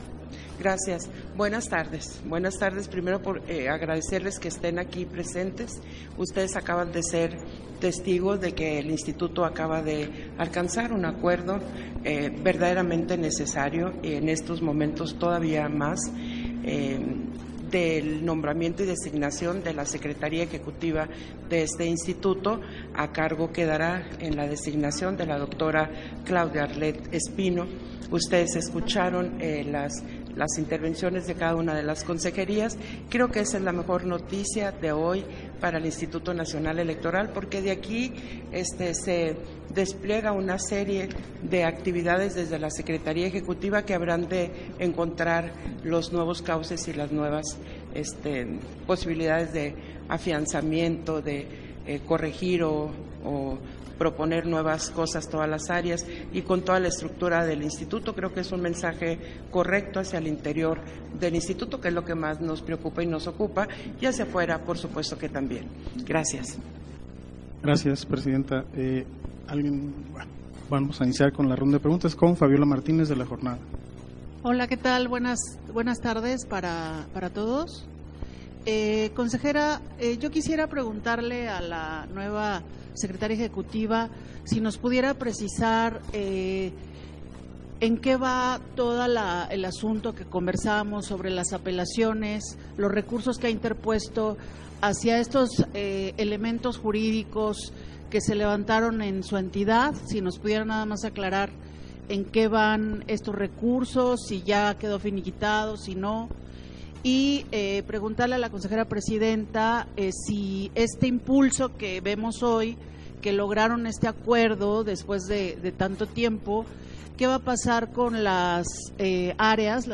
281124_AUDIO_CONFERENCIA-DE-PRENSA-CONSEJERA-PRESIDENTA - Central Electoral